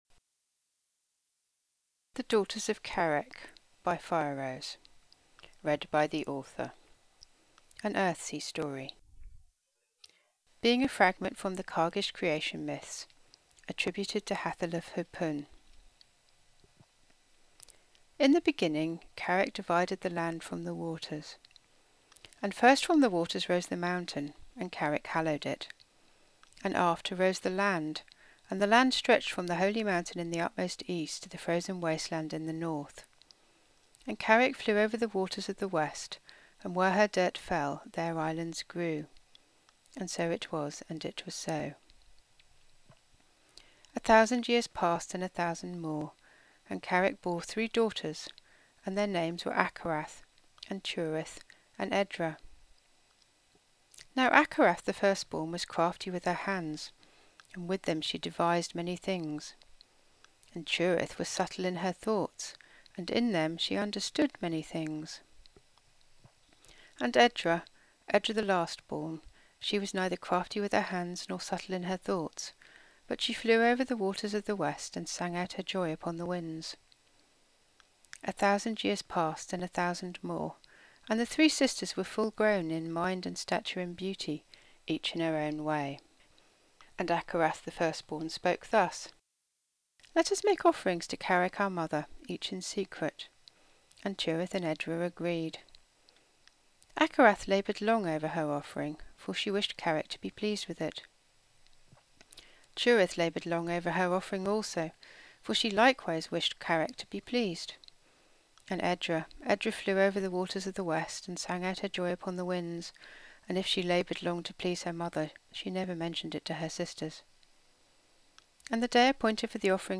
Ooh, more Earthsea podfic! *downloads*